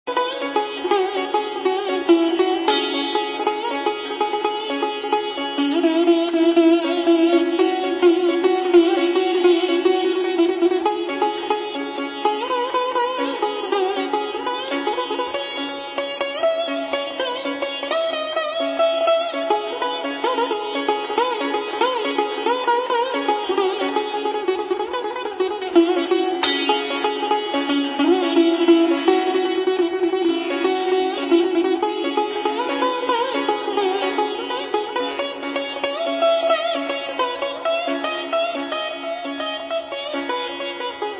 Ситар
sitar1.mp3